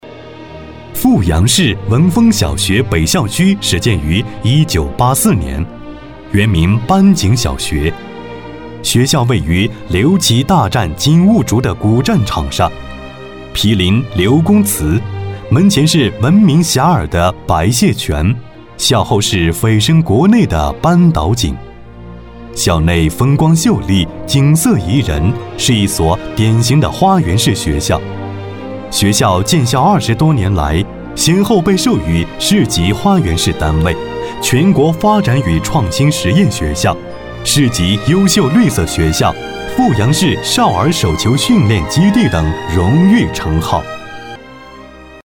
学校男21号
年轻时尚 学校专题
品质男音，大气沉稳、年轻时尚、洪亮。